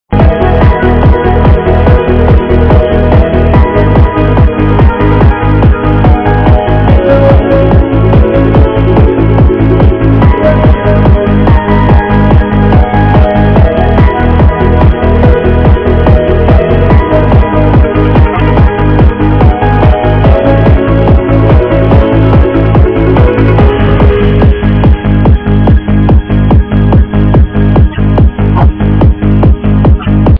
a cheesy eurodance track...